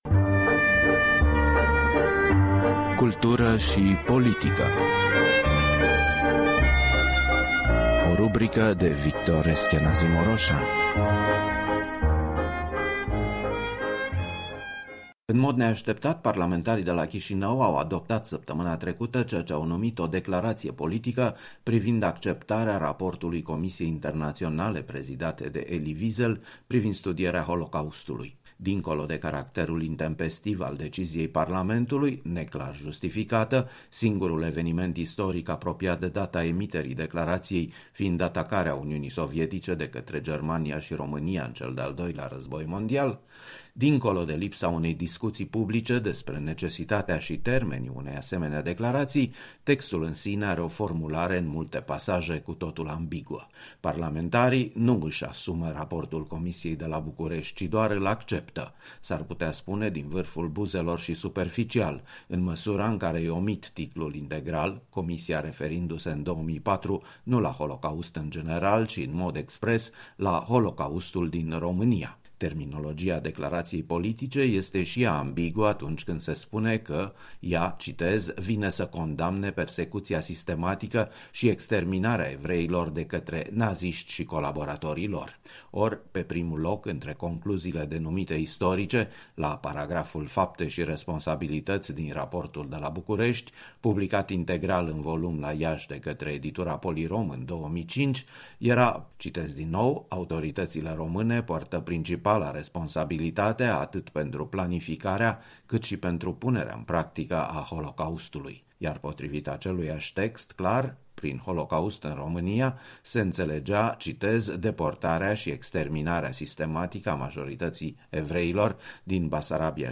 Cultură și politică: un interviu